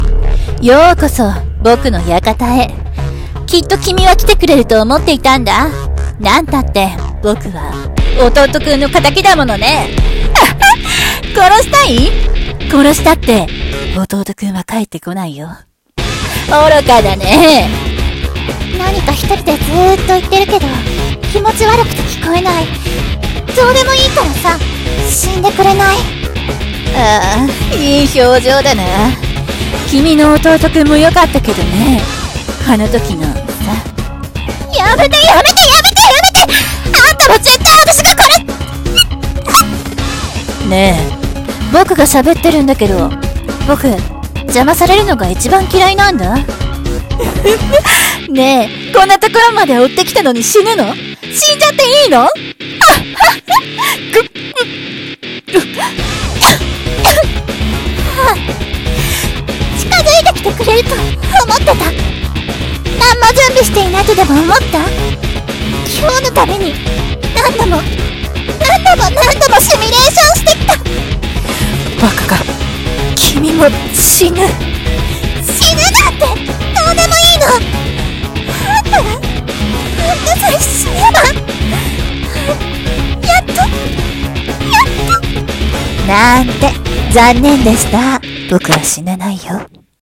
【声劇】悪魔の館